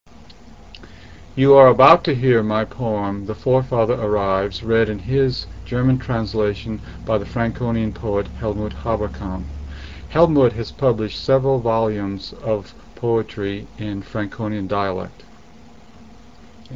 Audio Introduction
Read by Norbert Krapf